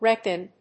レーピン